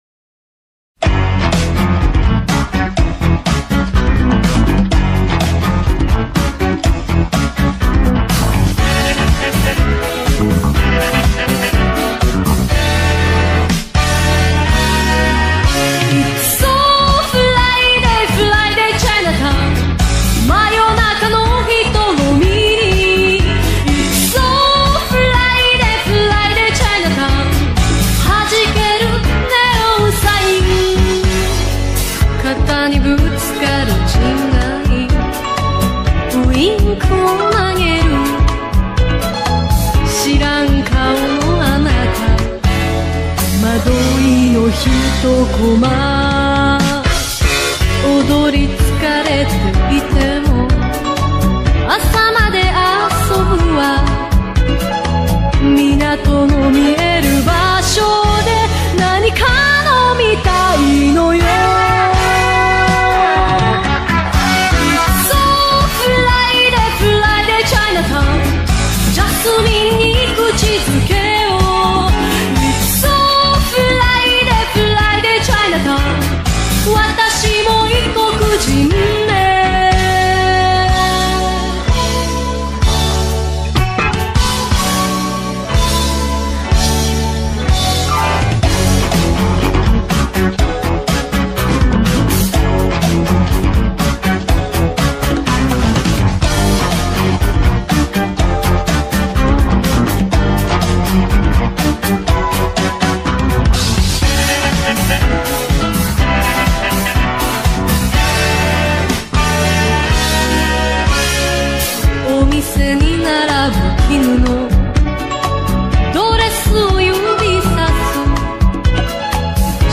японская певица и автор песен
который сочетает элементы поп-музыки и синти-попа